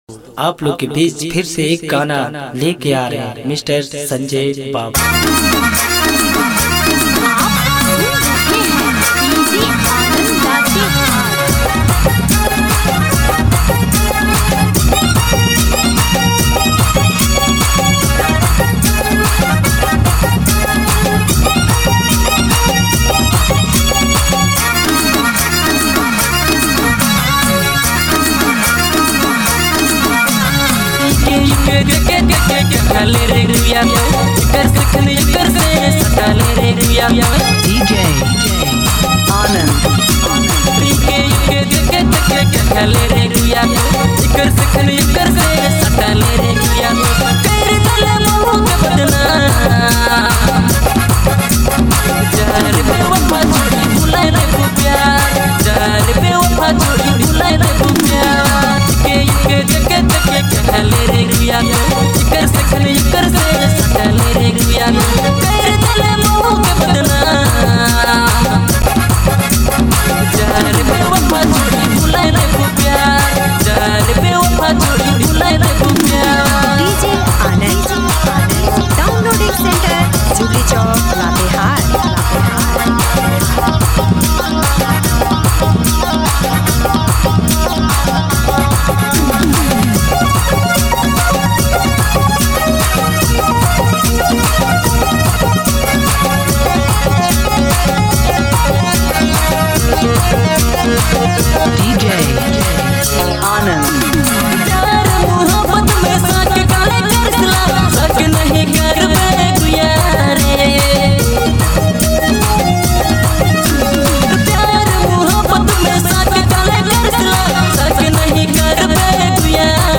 Nagpuri DJ hit